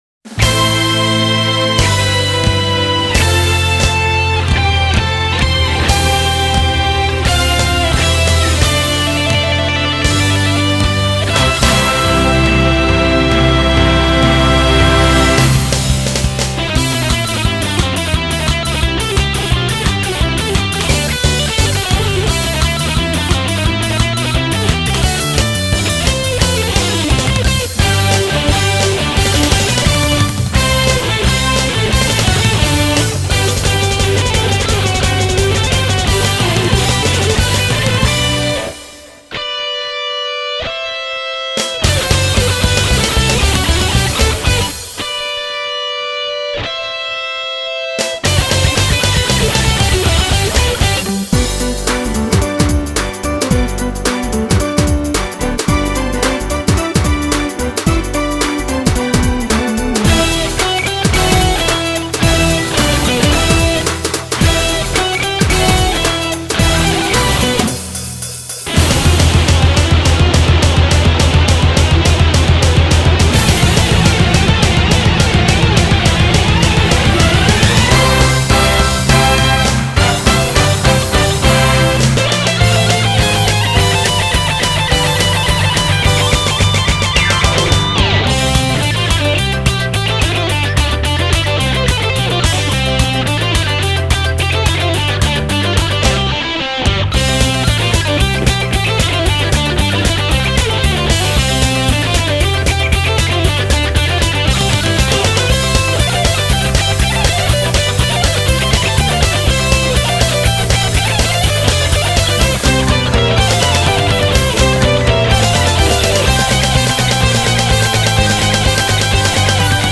BPM44-420
Audio QualityMusic Cut
quite a wild and weird one, lol.